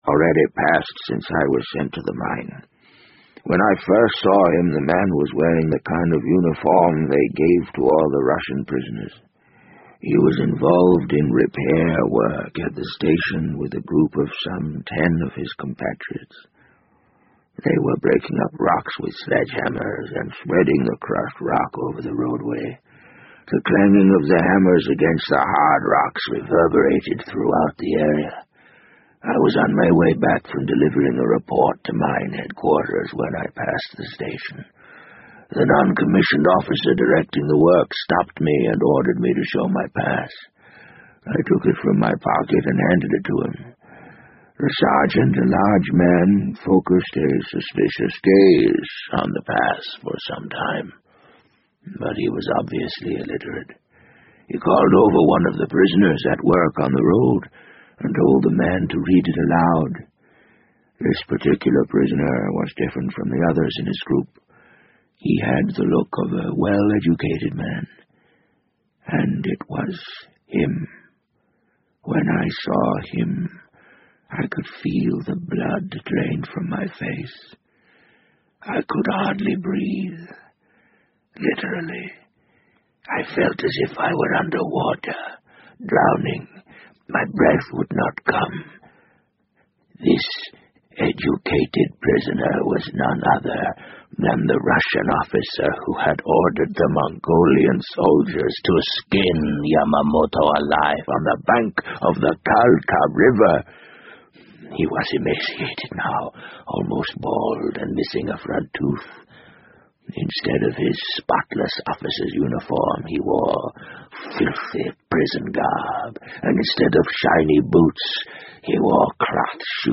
BBC英文广播剧在线听 The Wind Up Bird 014 - 4 听力文件下载—在线英语听力室